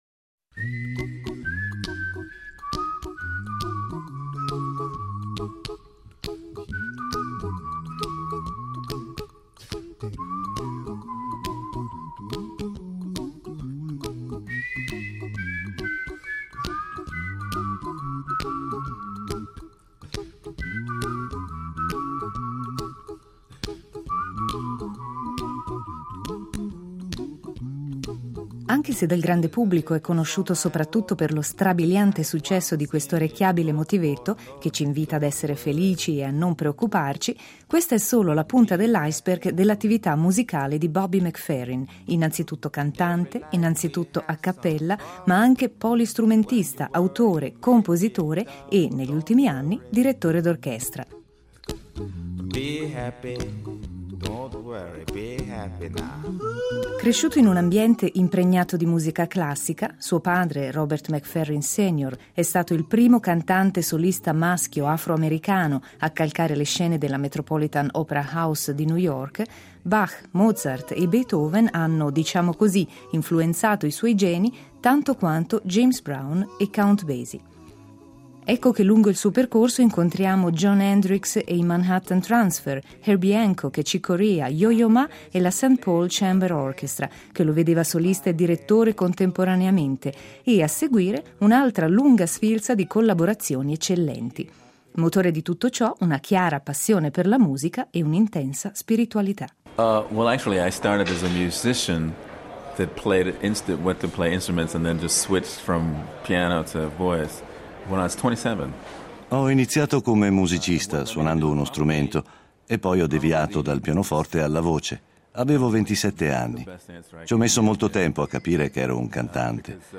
oggi vi riproponiamo la sua intervista